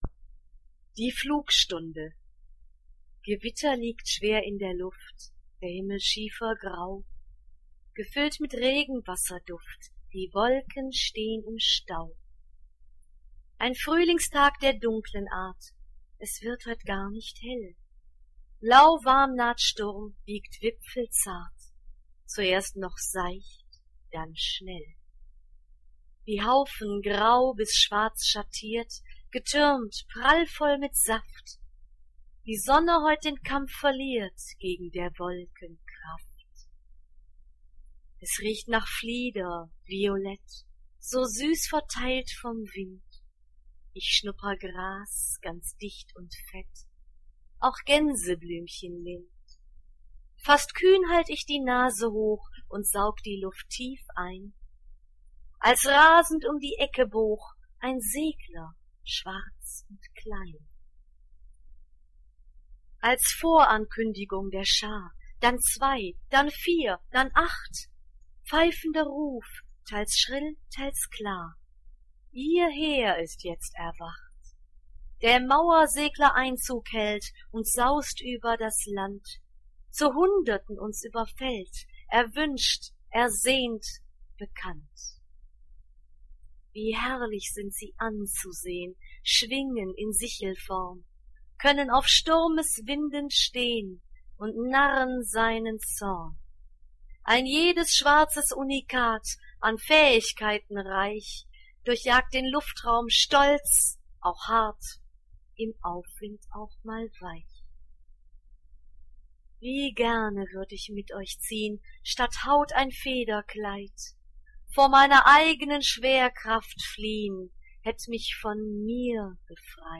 Leseproben